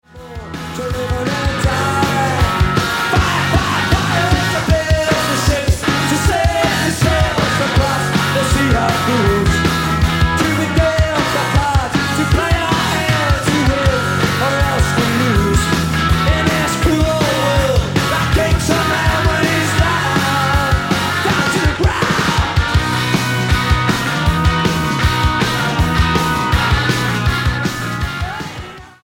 STYLE: Rock
(Live)